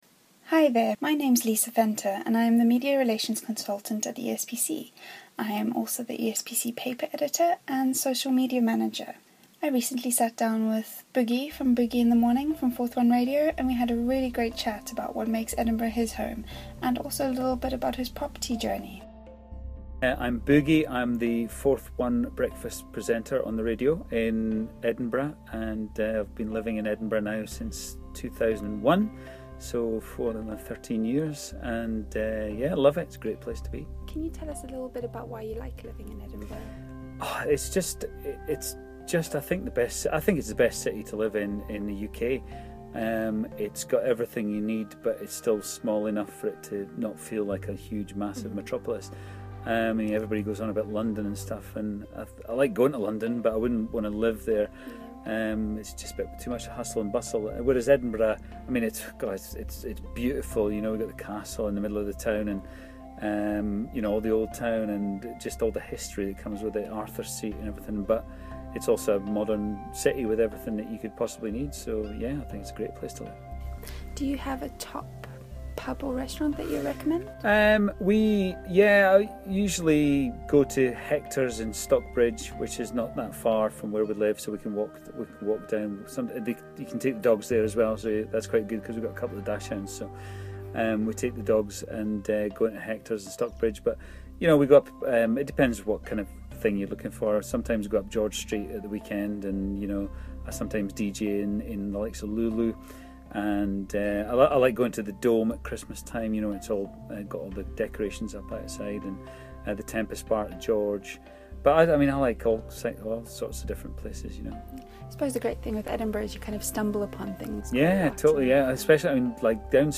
they had a great conversation about Edinburgh